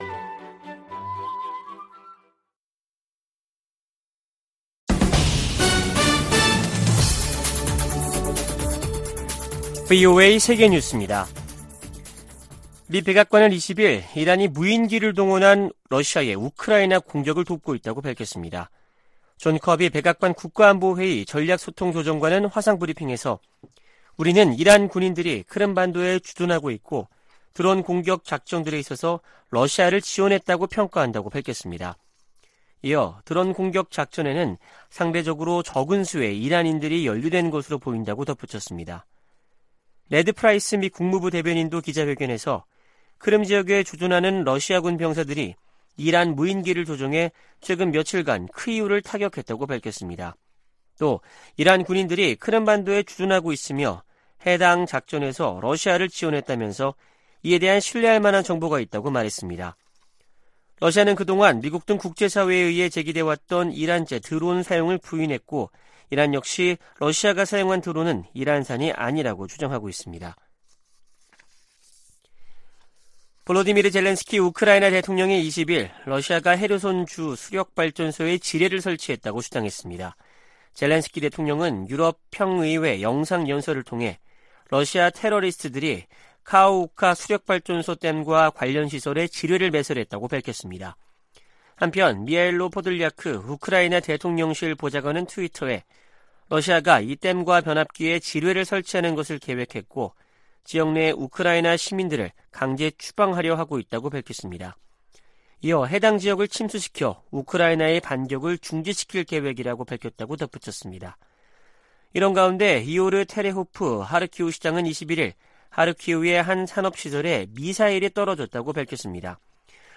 VOA 한국어 아침 뉴스 프로그램 '워싱턴 뉴스 광장' 2022년 10월 22일 방송입니다. 조 바이든 미국 대통령은 모든 방어역량을 동원해 한국에 확장억제를 제공하겠다는 약속을 확인했다고 국무부 고위당국자가 전했습니다. 미 국방부가 북한의 잠재적인 추가 핵실험 준비 움직임을 주시하고 있다고 밝혔습니다.